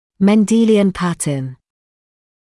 [men’diːlɪən ‘pætn] [мэн’диːлиэн ‘пэтн] закон(ы) Менделя